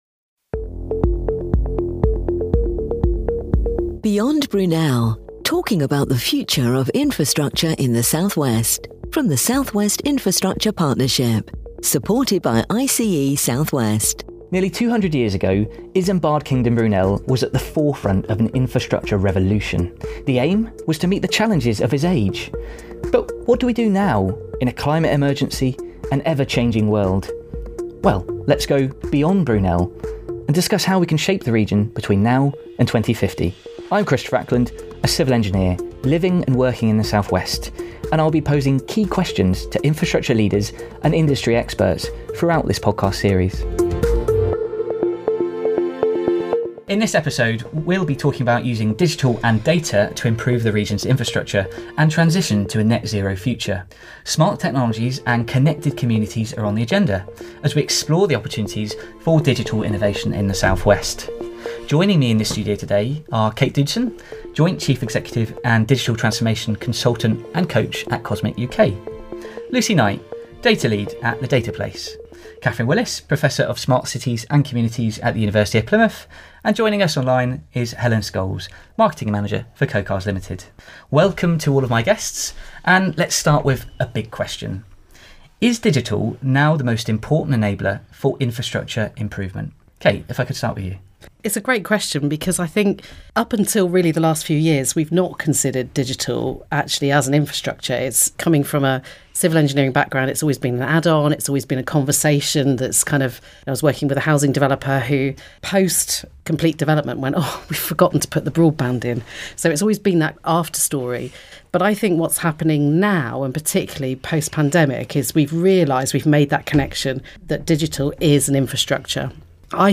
In Episode 2, our panel of experts discuss digital and data infrastructure across the South West and how they can be used to improve the region's connectivity and transition to a net zero future.